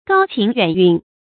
高情遠韻 注音： ㄍㄠ ㄑㄧㄥˊ ㄧㄨㄢˇ ㄧㄨㄣˋ 讀音讀法： 意思解釋： 見「高情遠致」。